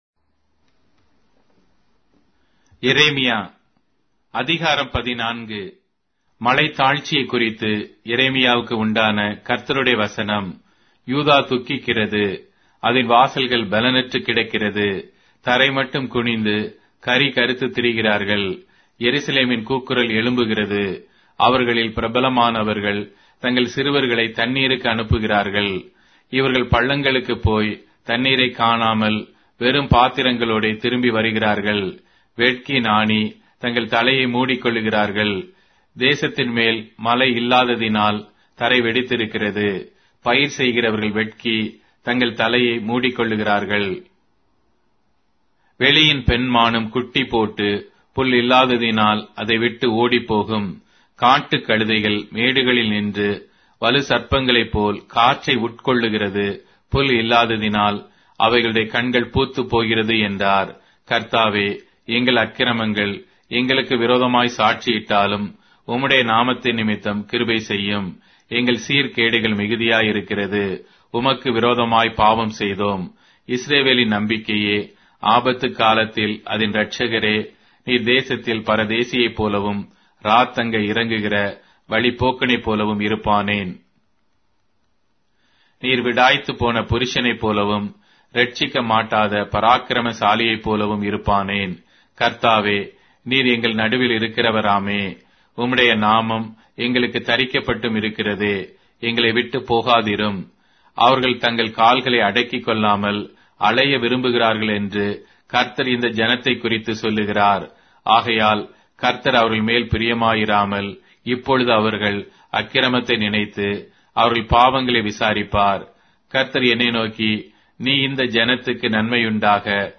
Tamil Audio Bible - Jeremiah 39 in Web bible version